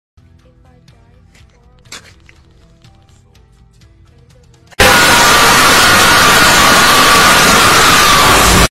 Tyler1 Scream